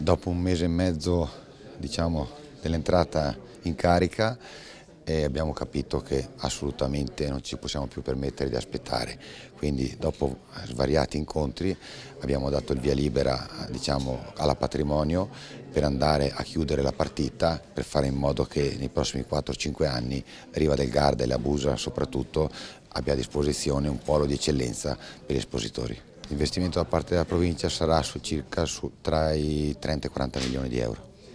L’annuncio dato alla presentazione della 91° edizione di Expo Riva Schuh, fiera dedicata al settore calzaturiero
INTERVISTA_FAILONI_09_01_(1).wav